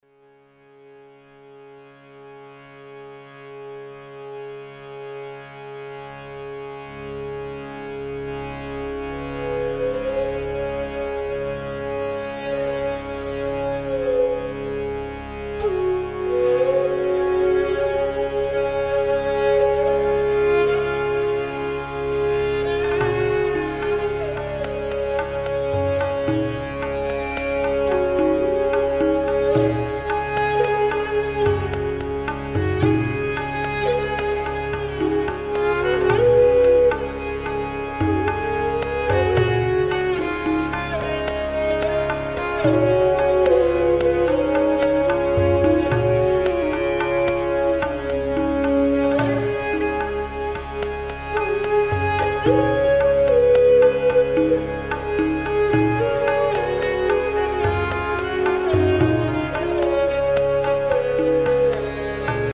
Эсраж
Это дает звук очень похожий на сарангу но, играть на нем становится легче.
esraj2.mp3